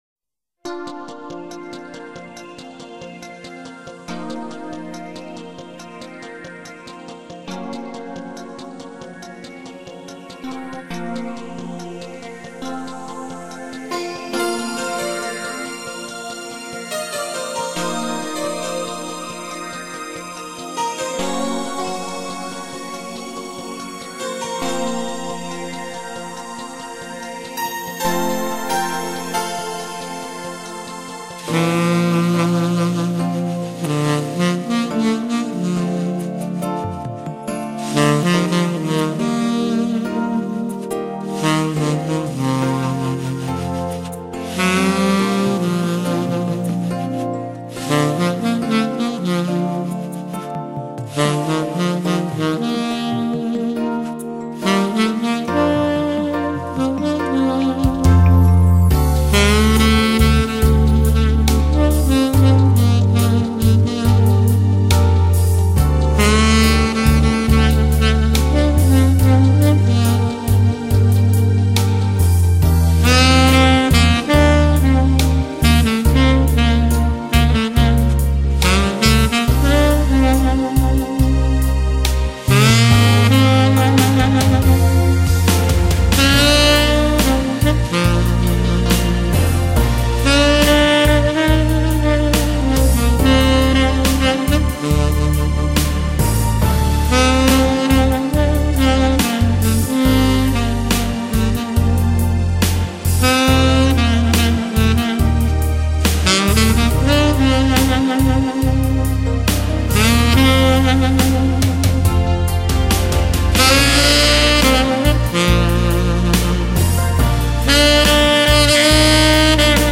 萨克斯
浪漫的萨克斯旋律，让你在休闲生活中享受优质的音乐感受！
动依旧，情挑心韵，抒情款款，柔情萨克斯，带你重温往日浪漫！